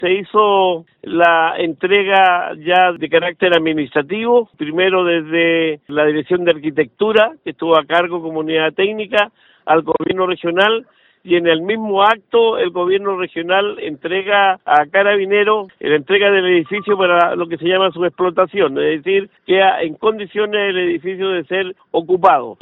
Al respecto, el gobernador Regional, Luis Cuvertino, señaló que tras el traspaso oficial del recinto policial, Carabineros ya puede hacer uso del edificio.